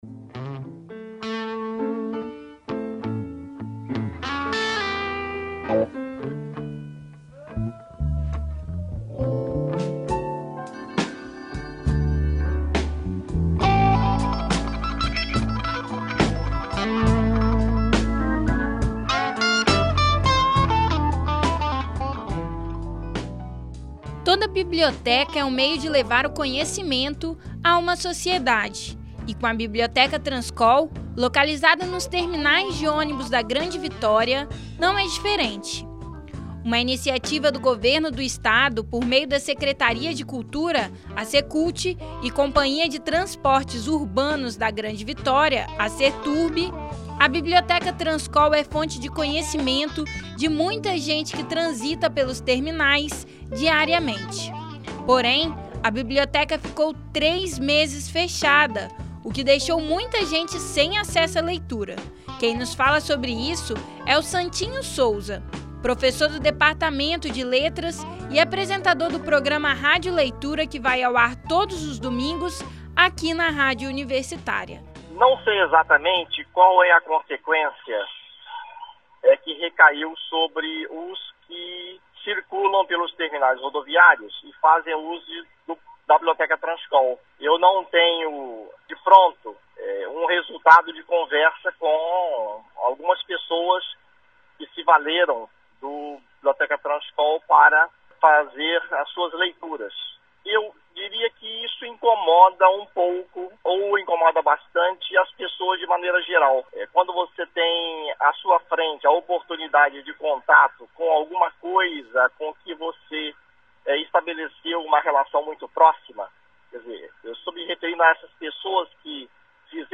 Mas entenda as questões relativas à Biblioteca Transcol na matéria que foi ao ar no programa Revista Universitária.